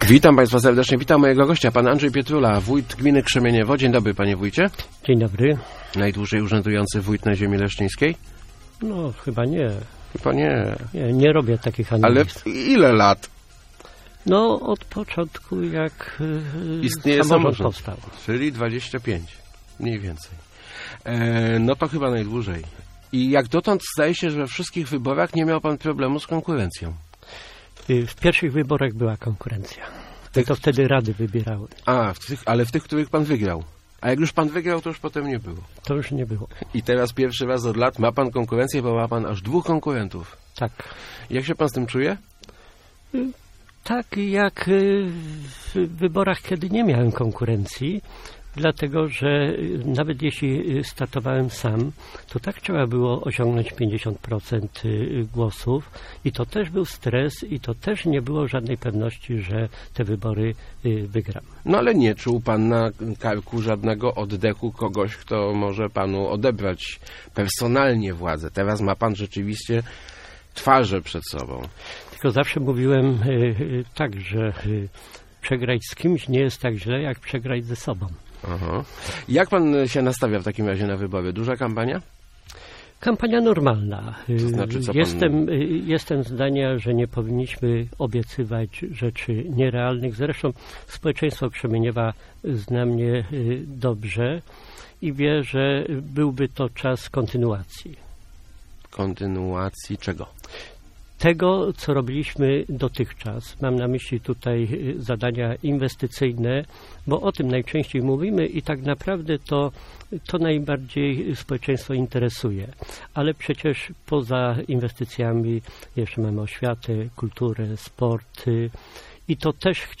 Kiedy rozpoczynaliśmy inwestycje infrastrukturalne, mieszkańcy mieli do wyboru sieć kanalizacyjną i gazową, wybrali gazową - mówił w Rozmowach Elki wójt Krzemieniewa Andrzej Pietrula.